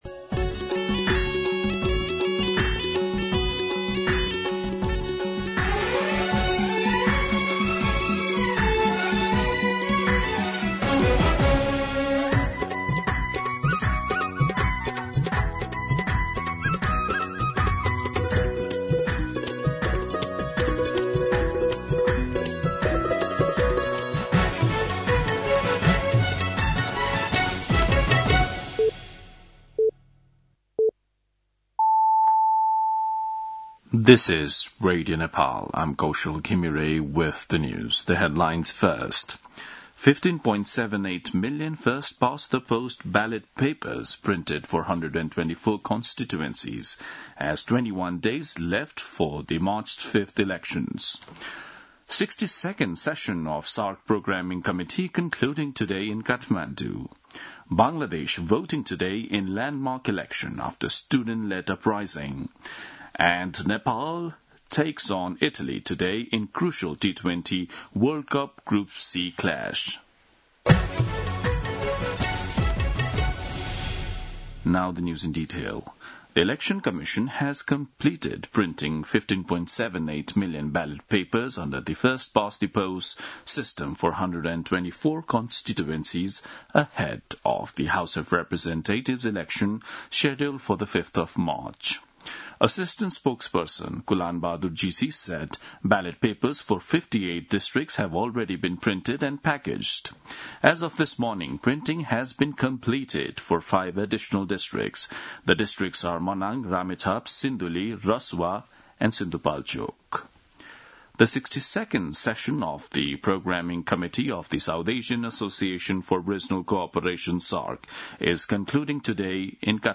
दिउँसो २ बजेको अङ्ग्रेजी समाचार : २९ माघ , २०८२